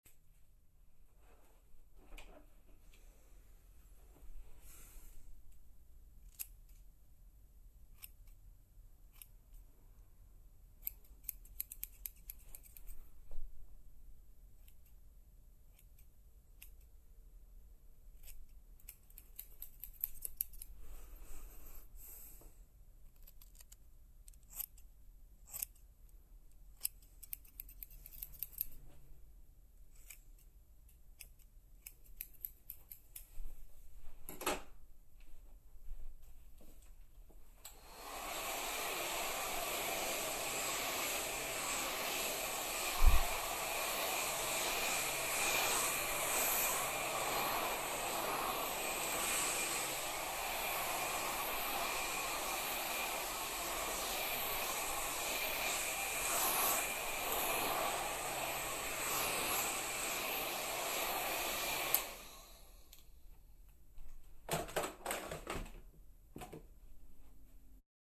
ネットで見つけたホロフォニクスで録音されたと思われる音源。
髪をハサミで切る音・ドライヤーをかけられる音
HaircutDryer.mp3